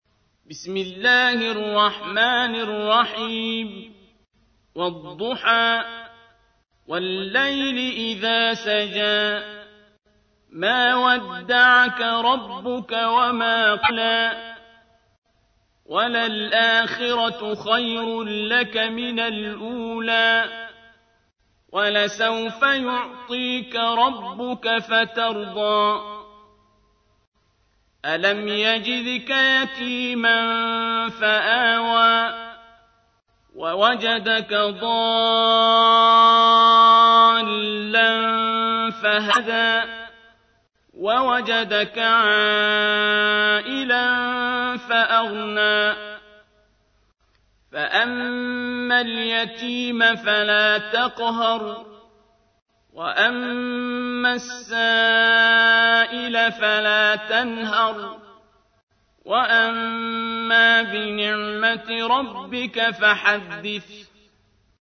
تحميل : 93. سورة الضحى / القارئ عبد الباسط عبد الصمد / القرآن الكريم / موقع يا حسين